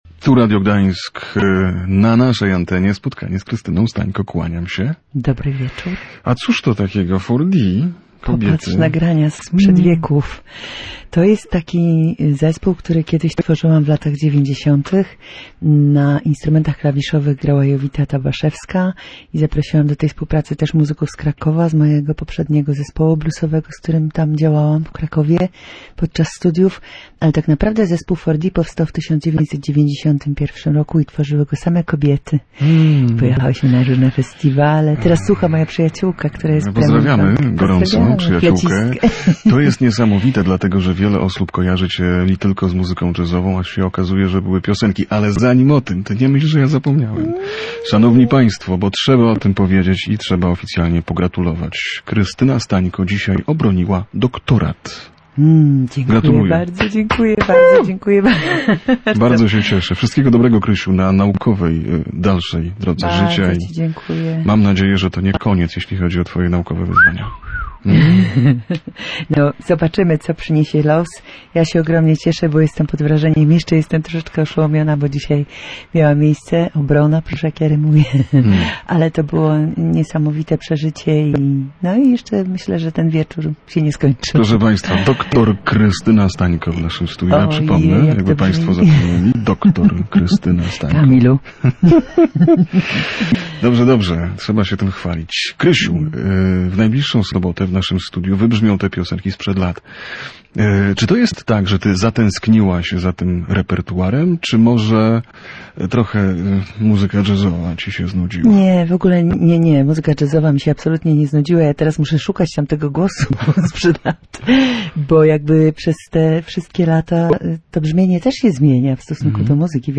„Pociąg do piosenki” w zaskakującym wydaniu. A wszystko wykonane przez „family band” [POSŁUCHAJ ROZMOWY]